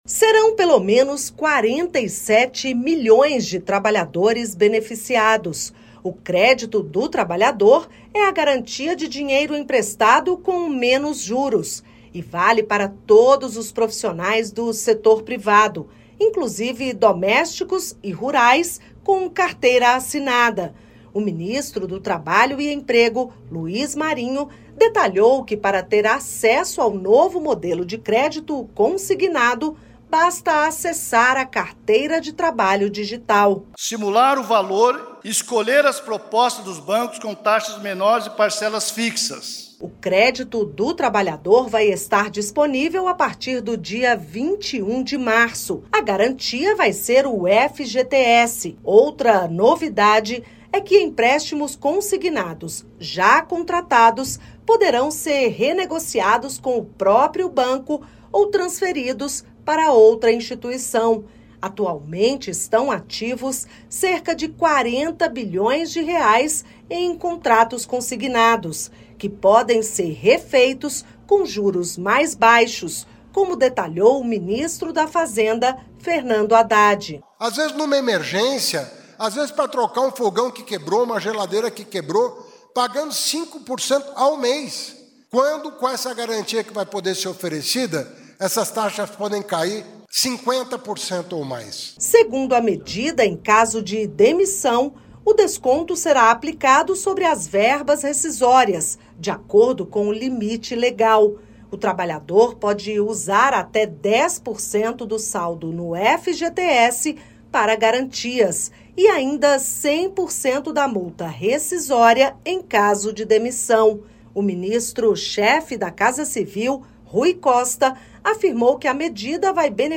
Para dar mais eficiência e combater fraudes, o Benefício de Prestação Continuada conta com novas regras. Entenda na reportagem.